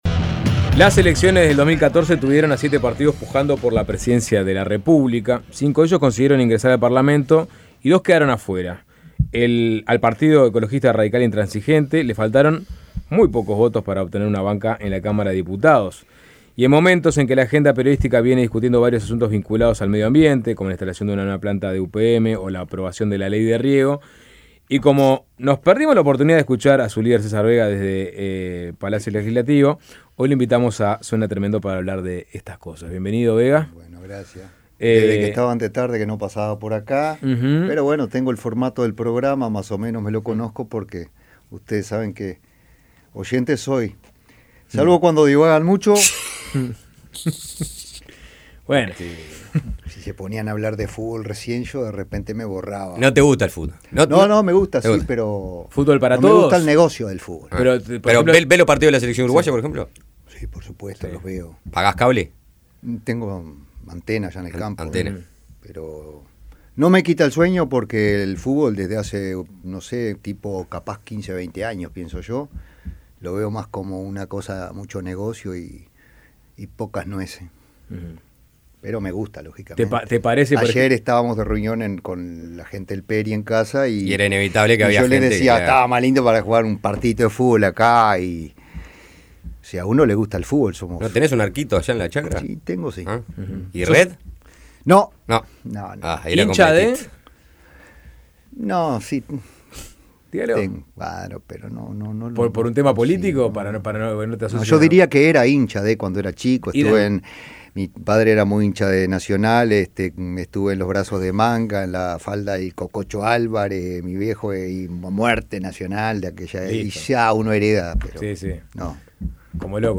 El presidente del Partido Ecologista Radical Intransigente (PERI), César Vega, dijo en Suena Tremendo que si no llega al Parlamento en la próxima elección, dejará de intentarlo.